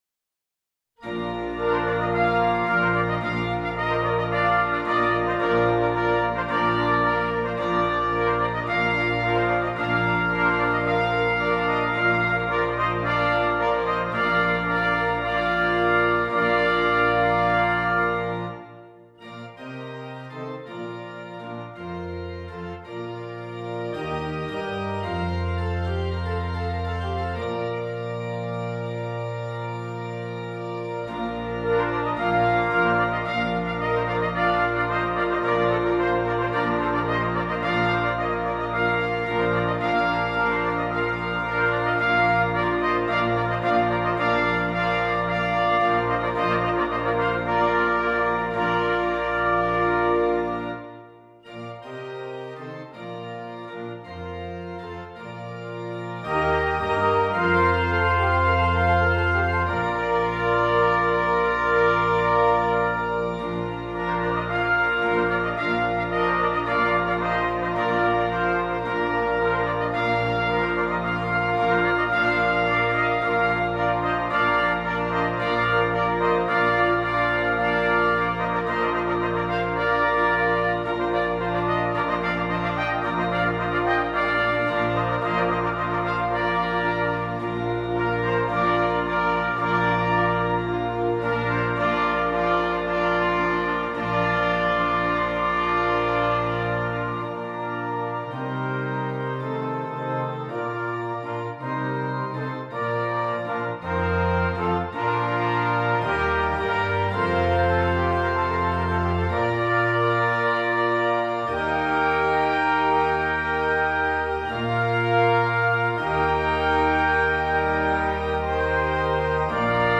• Brass Quintet and Organ
Traditional Carol